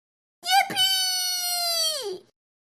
sounds / mob / villager / hit2.ogg